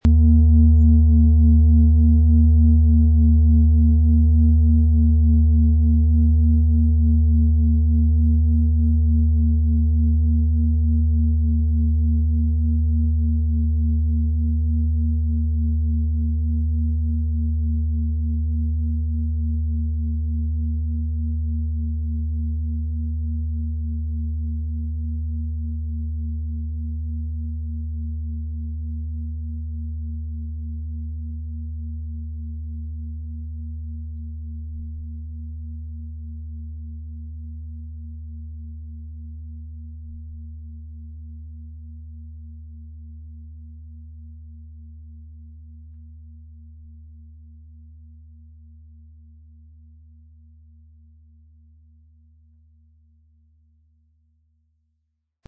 XXXL Planeten-Fußreflexzonen-Klangschale mit Wasserstoffgamma & Mond
Die feinen, harmonischen Vibrationen durchströmen Körper, Geist und Seele und führen dich sanft zurück in deine Mitte - getragen von universeller Harmonie und nährender Mondenergie.
PlanetentonWasserstoffgamma & Biorhythmus Seele (Höchster Ton)